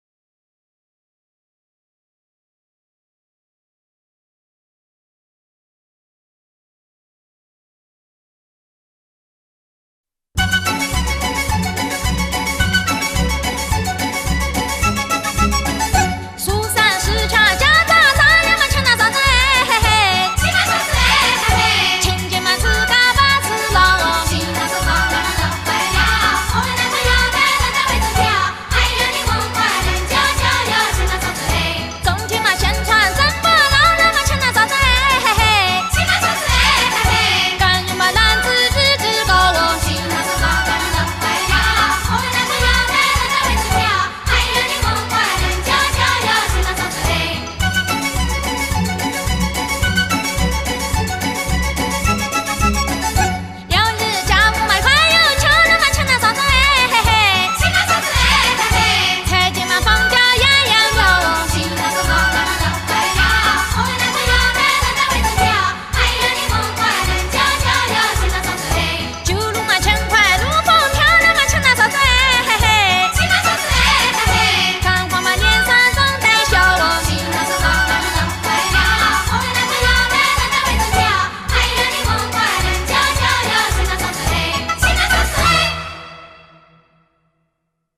民歌
当涂民歌